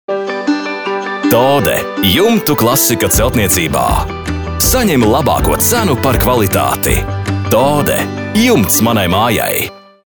RADIO REKLĀMAS